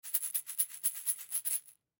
На этой странице собраны разнообразные звуки, связанные с пищевой солью: хруст кристаллов, шум пересыпания, звук растворения в воде и другие.
Звук рассыпающейся соли из солонки